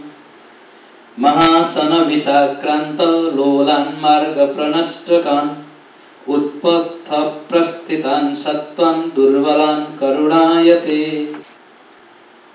anuṣṭubh